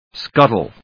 音節scut・tle 発音記号・読み方
/skˈʌṭl(米国英語)/